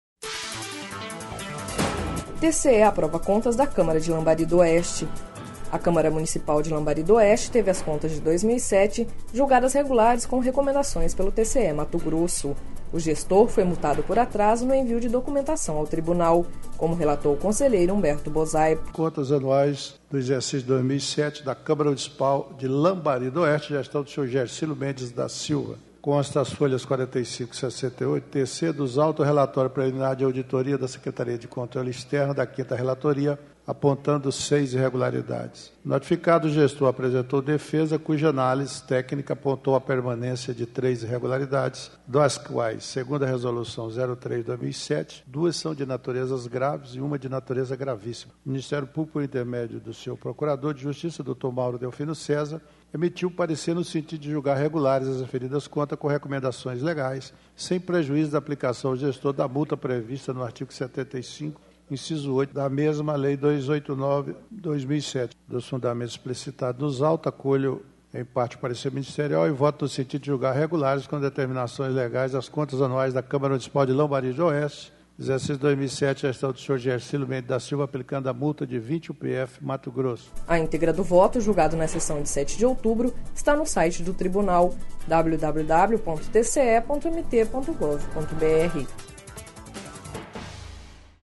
Sonora: Humberto Bosaipo - conselheiro do TCE-MT